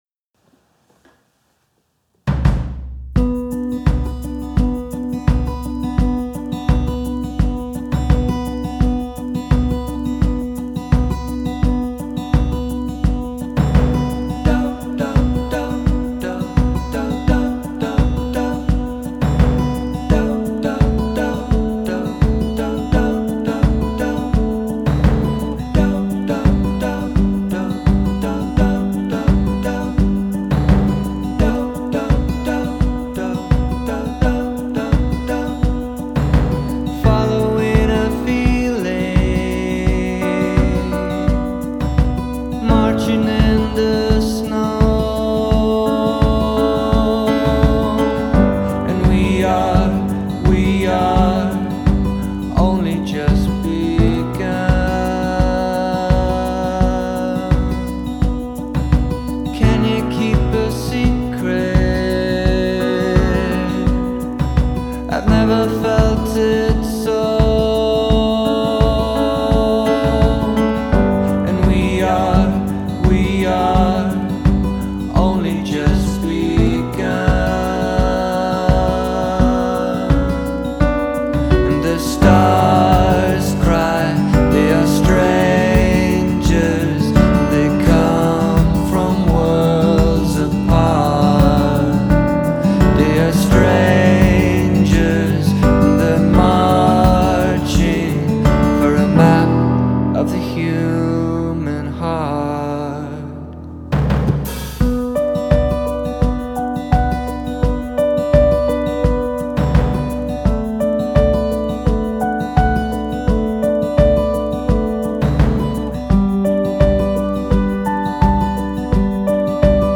Genre: Indie, Folk